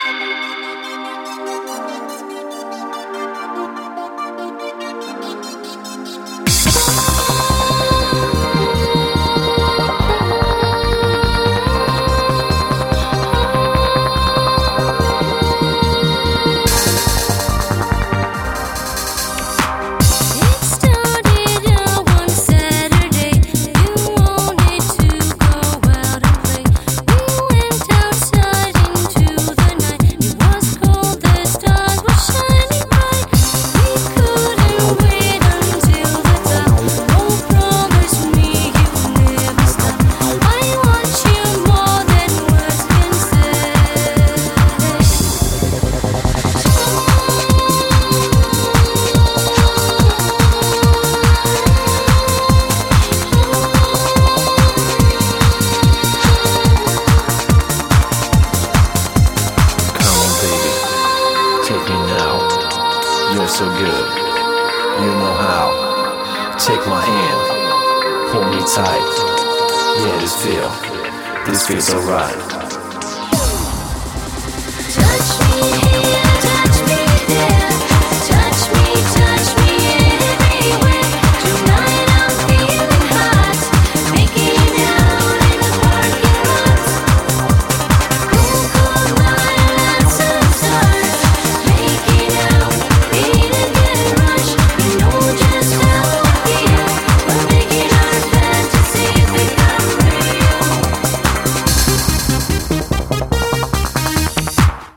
BPM72-144
Audio QualityMusic Cut